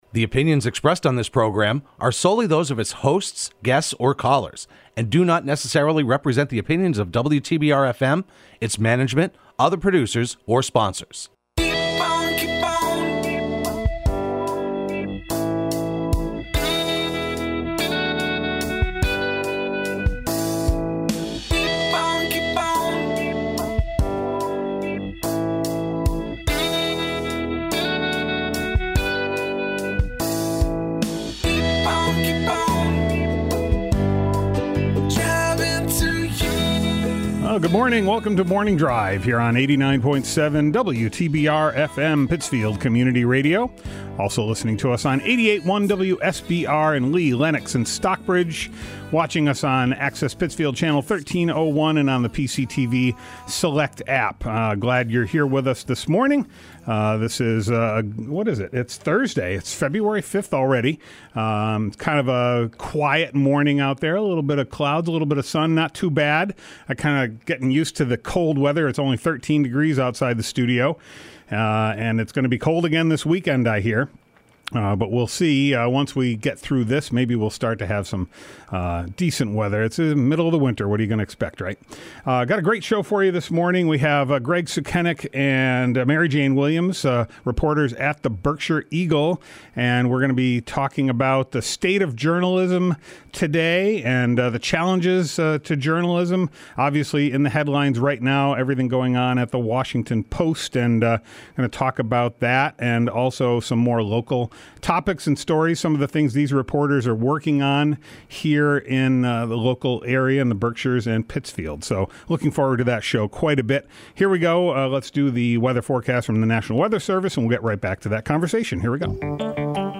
into the WTBR studio to talk about current events and the media landscape.